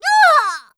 cleric_f_voc_die_b.wav